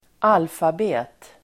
Ladda ner uttalet
alfabet substantiv, alphabet Uttal: [²'al:fabe:t] Böjningar: alfabetet, alfabet, alfabeten Definition: skrivtecknen i ett språk: "a, b, c" osv (the set of characters used to write a language: "a, b, c ...")